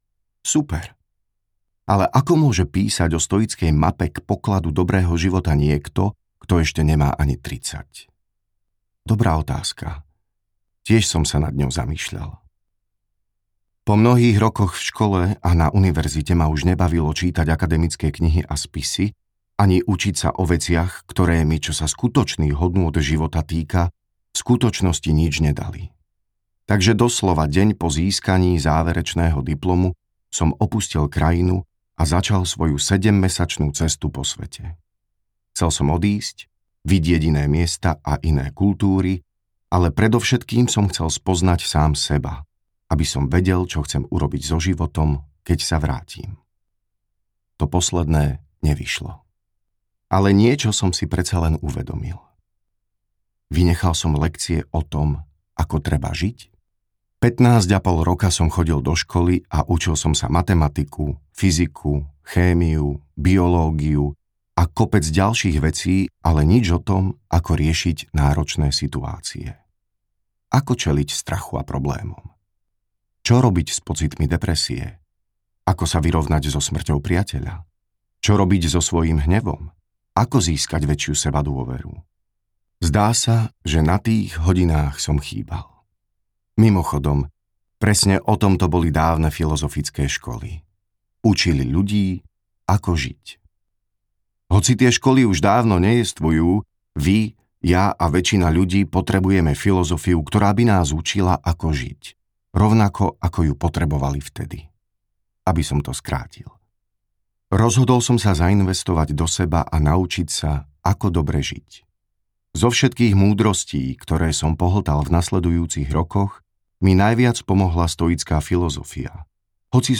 Audiokniha Malá kniha stoicizmu